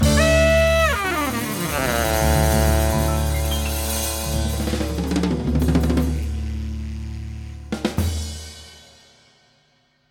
Copyright Free Jazz Saxophone TV Show End Theme